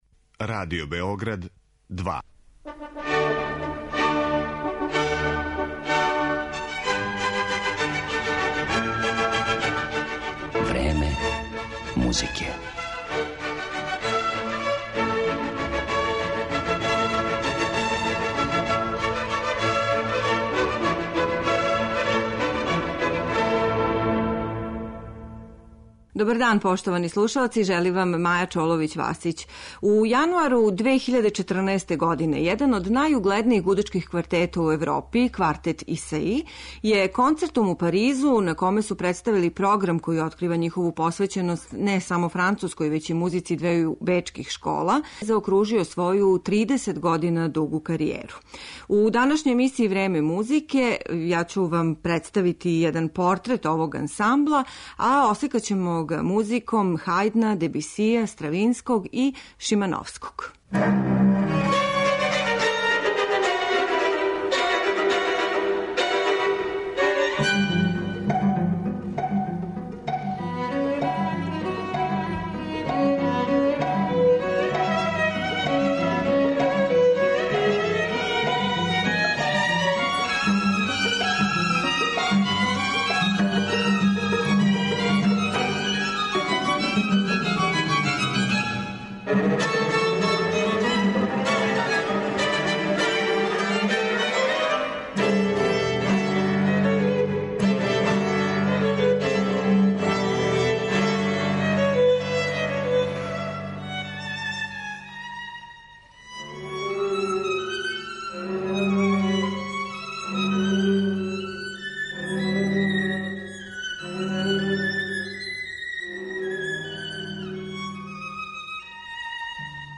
Изузетан музички укус и рафинираност свирања, као и сензуалност и техничко мајсторство, квалитети су који су овај квартет током тридесет година постојања сврстали међу најеминентније, али и најаристократскије камерне саставе XX века.